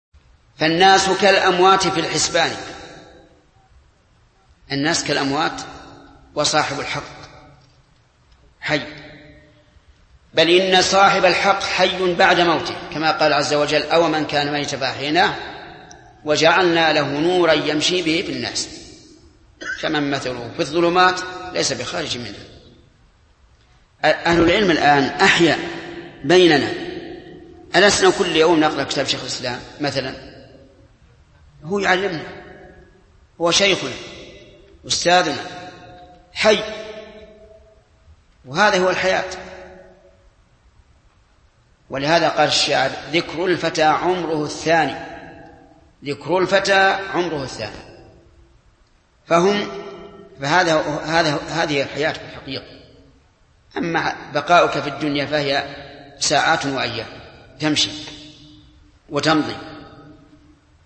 Format: MP3 Mono 22kHz 32Kbps (VBR)